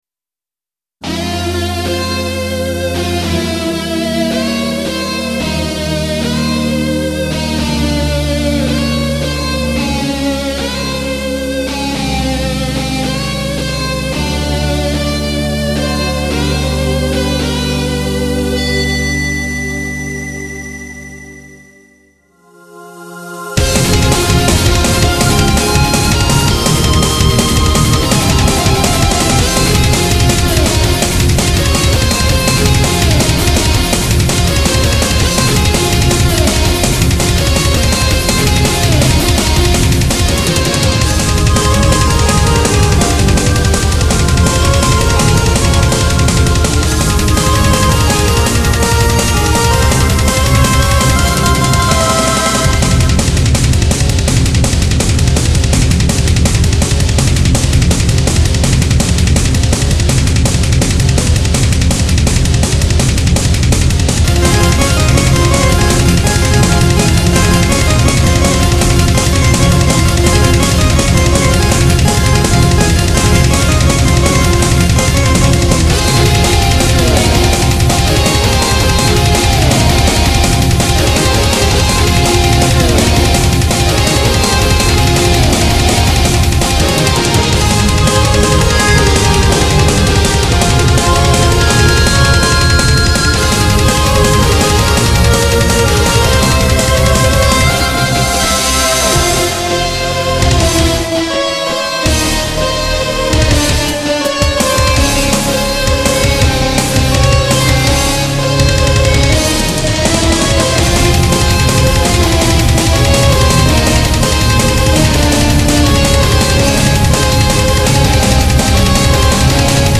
YAMAHA MU2000を使ってゲームBGMのメタルアレンジをしています